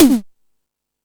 Hip Hop(30).wav